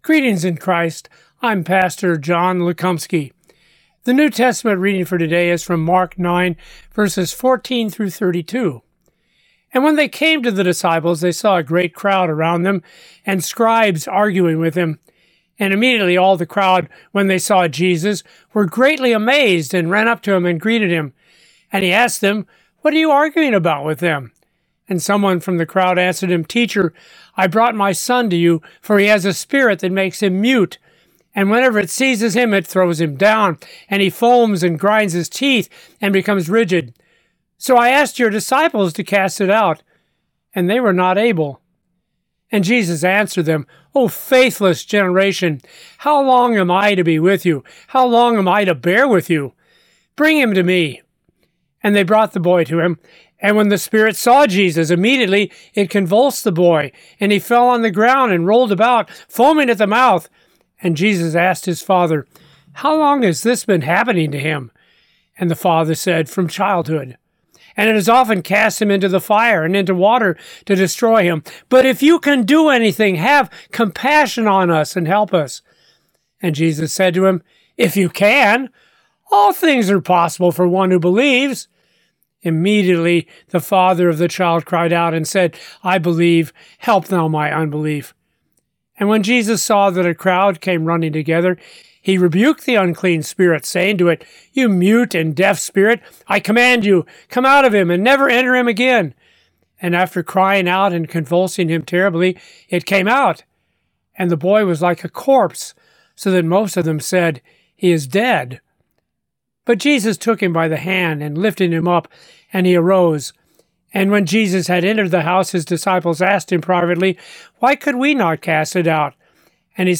Morning Prayer Sermonette: Mark 9:14-32
Hear a guest pastor give a short sermonette based on the day’s Daily Lectionary New Testament text during Morning and Evening Prayer.